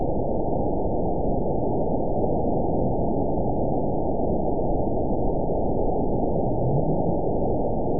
event 921889 date 12/20/24 time 09:37:31 GMT (4 months, 2 weeks ago) score 9.55 location TSS-AB02 detected by nrw target species NRW annotations +NRW Spectrogram: Frequency (kHz) vs. Time (s) audio not available .wav